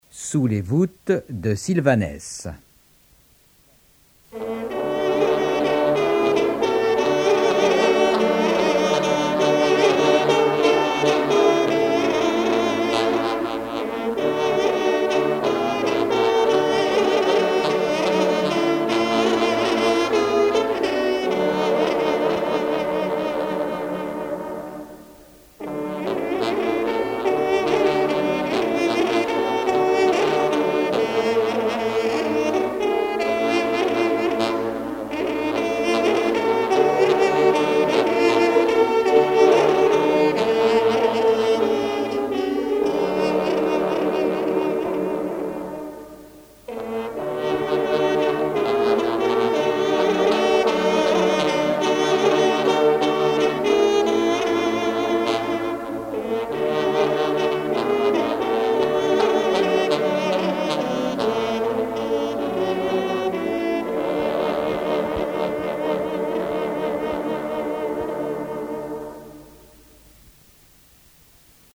trompe - Fanfares et fantaisies de concert
circonstance : vénerie